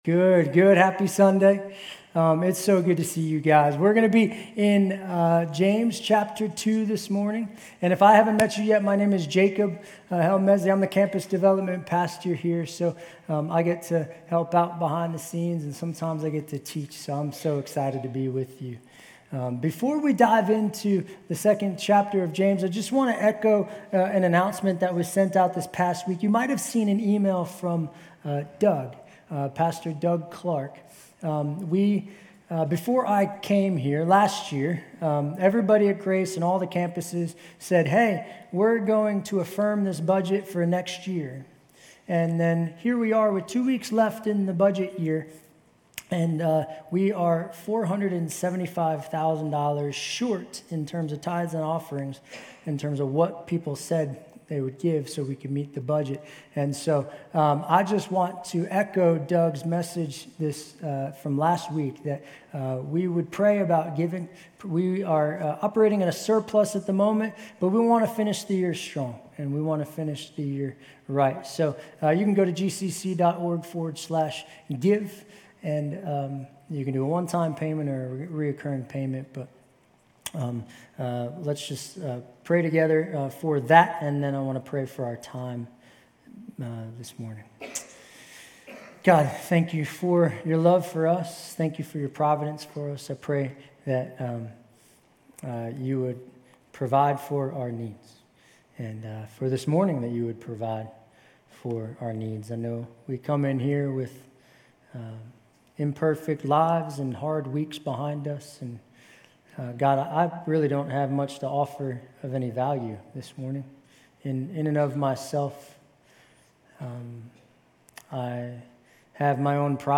GCC-UB-June-25-Sermon.mp3